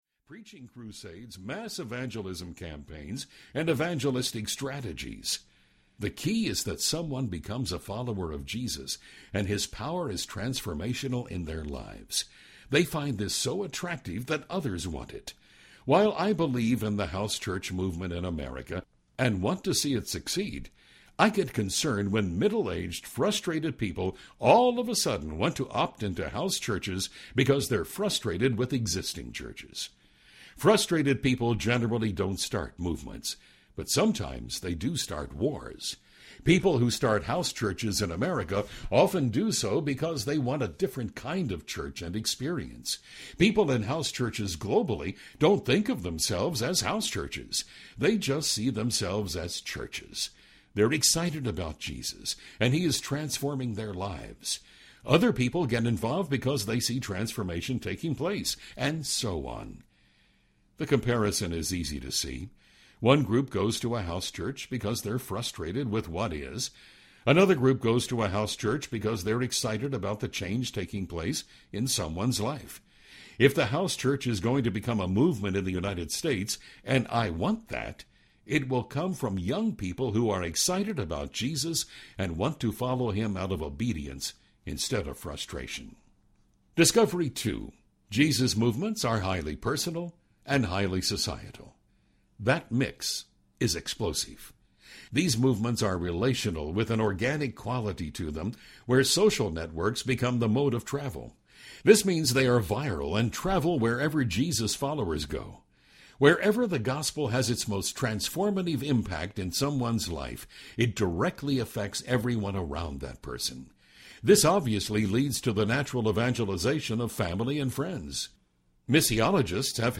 The Multiplying Church Audiobook
6.2 Hrs. – Unabridged